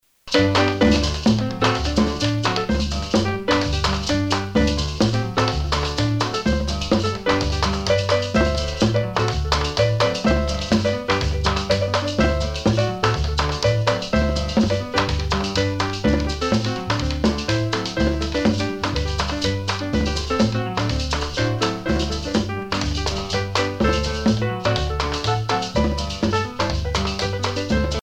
danse : merengue
Pièce musicale éditée